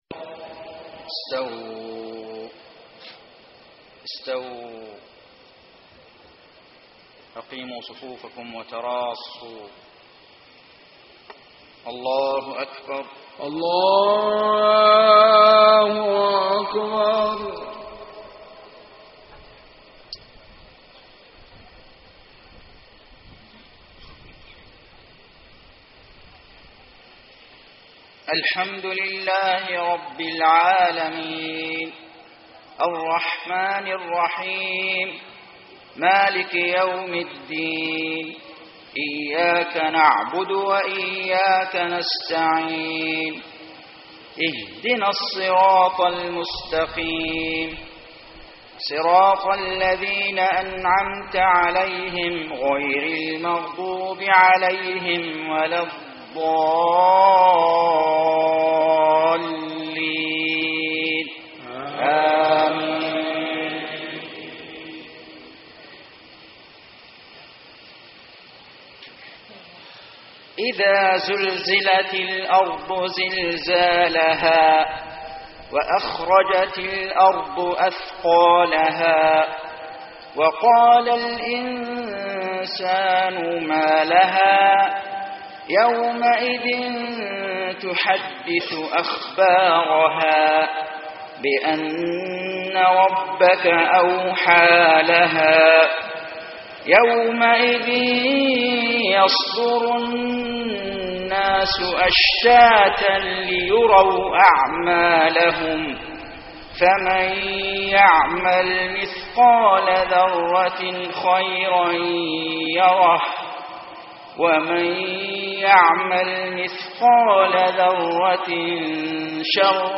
صلاة المغرب 1-6-1434 من سورتي الزلزلة و النصر > 1434 🕋 > الفروض - تلاوات الحرمين